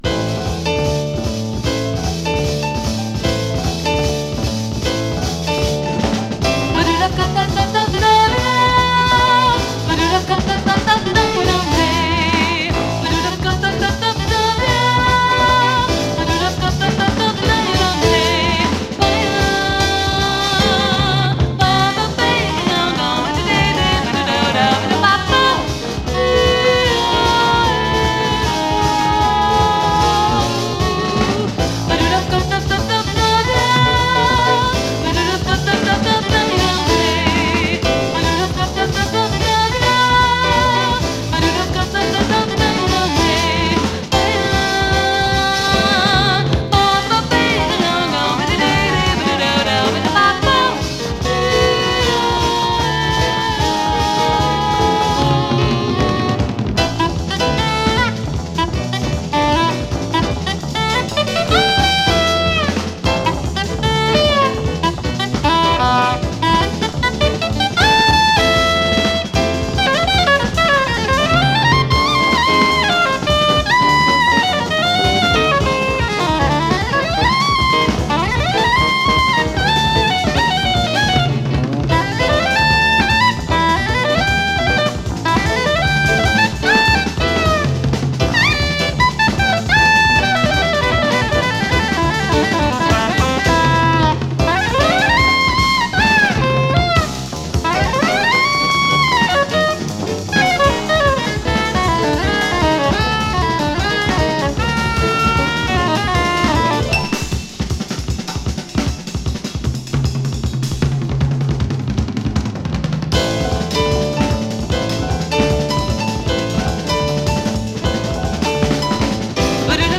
幻想的なイントロからスタートする